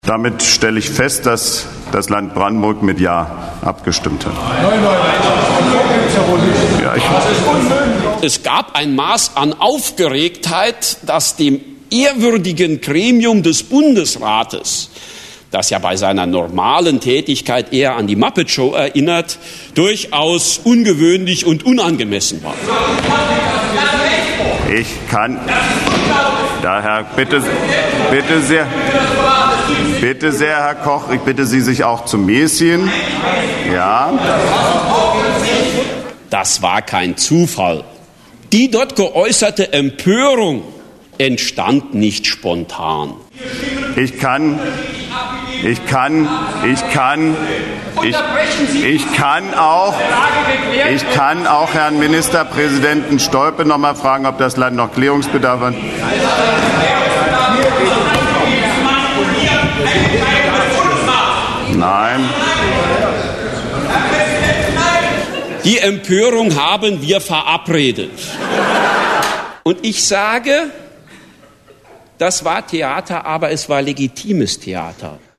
Was: Collage Eklat im Bundesrat
* Klaus Wowereit, Bundesratspräsident
* Roland Koch, Ministerpräsident Hessen
* Peter Müller, Ministerpräsident Saarland
* Bundesrat, Berlin
* Saarländisches Staatstheater, Saarbrücken